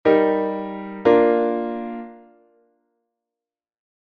8. armadura 2 bemois, dous acordes: sol-mi-sol-si; si-re-fa-siIsto é unha cadencia rota.
11._plagal_en_sib_M.mp3